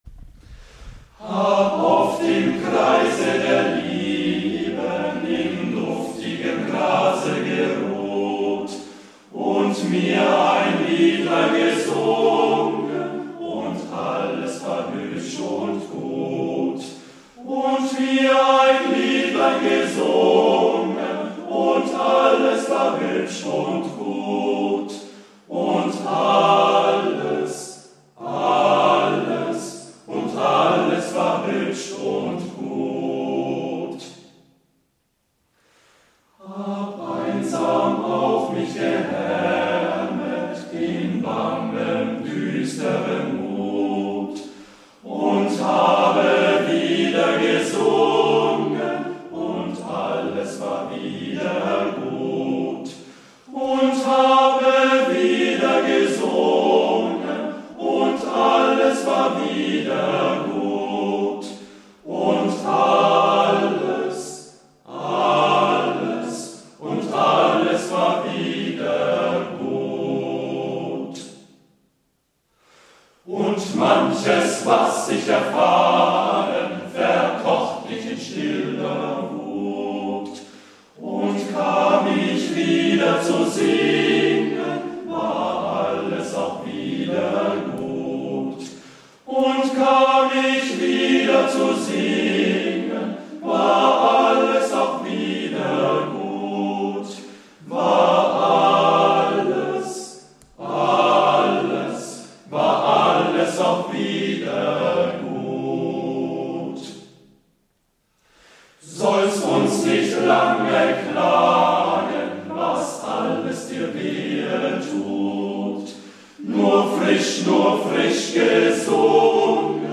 Webbasierte Gesangsprobe